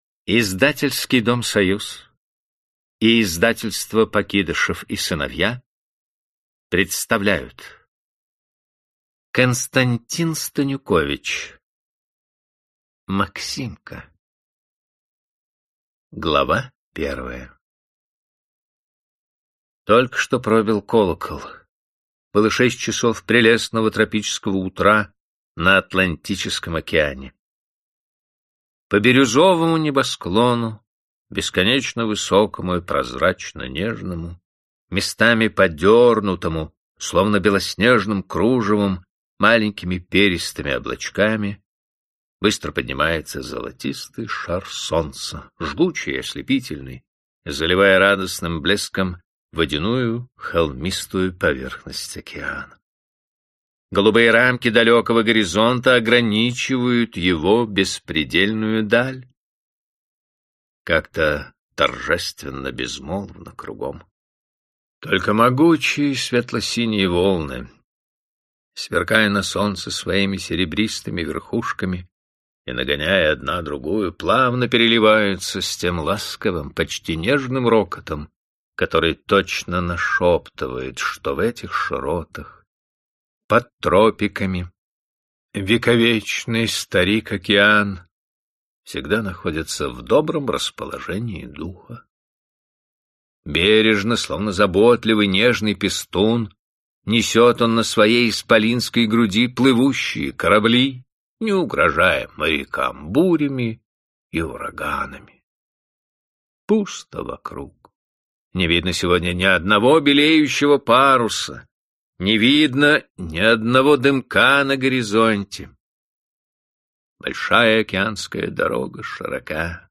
Аудиокнига Максимка | Библиотека аудиокниг